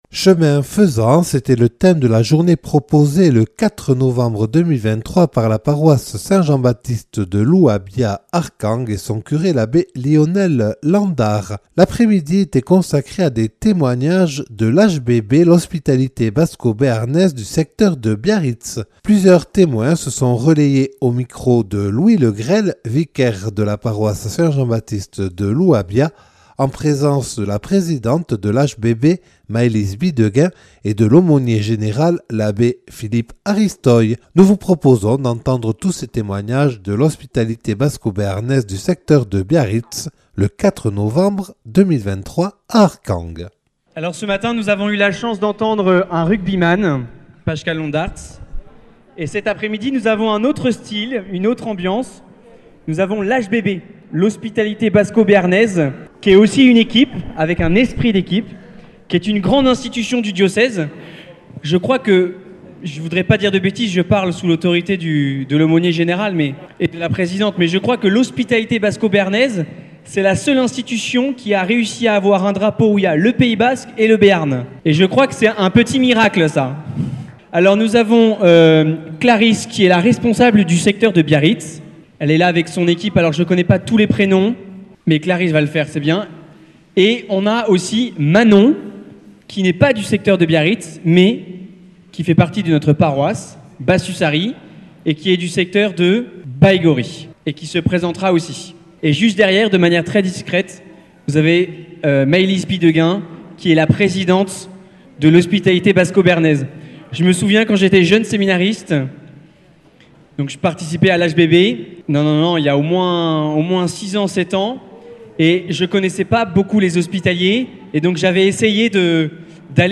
Témoignages de membres de l'Hospitalité Basco-Béarnaise du secteur de Biarritz.